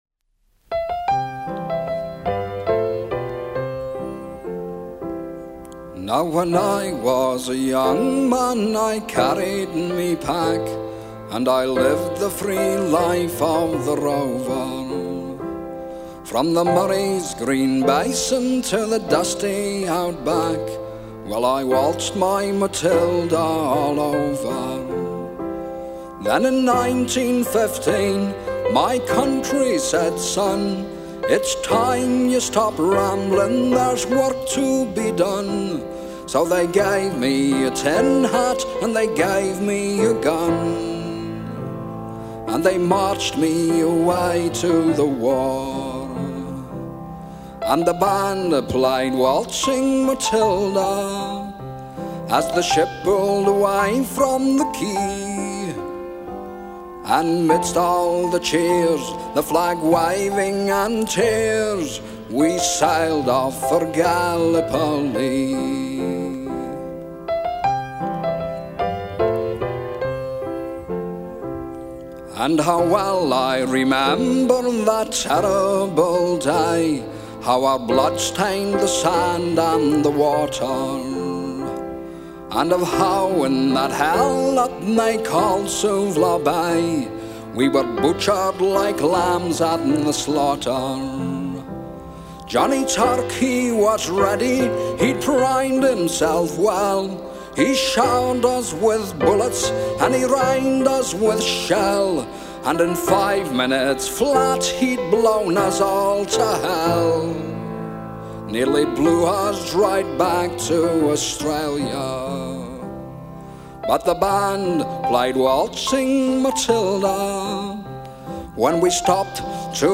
the finest anti-war song I've ever heard